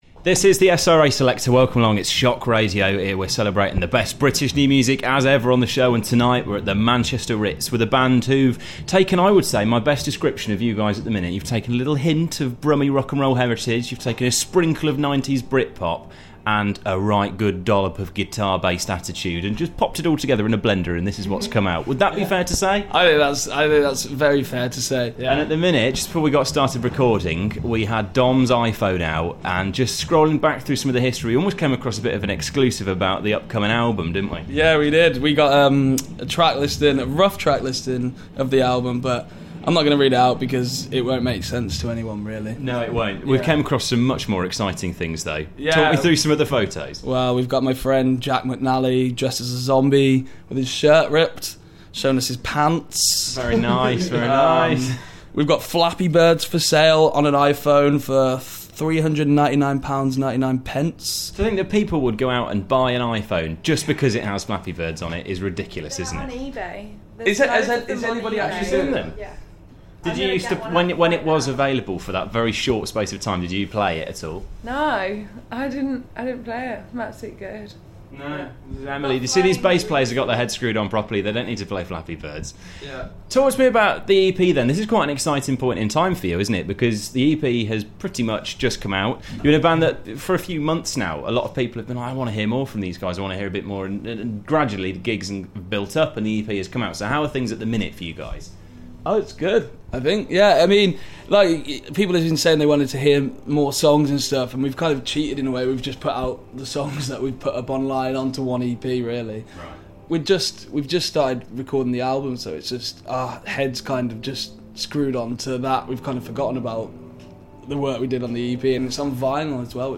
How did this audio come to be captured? Birmingham's own Superfood chatting at Manchester Ritz